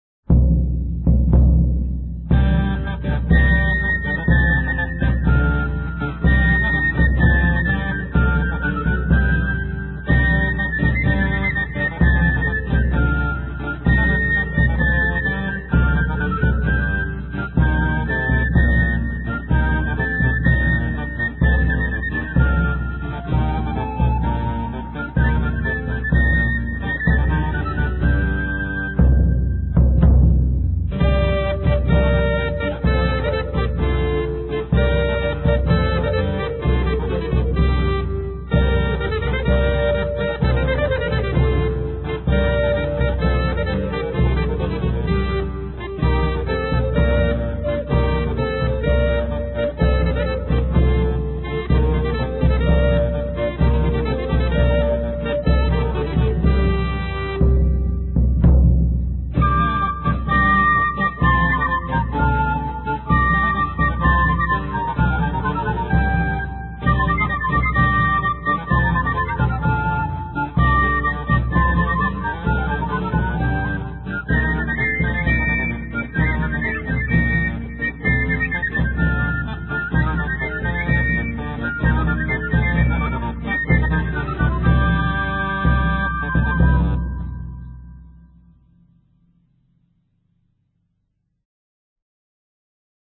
Mono, 1:32, 16 Khz, (file size: 183 Kb).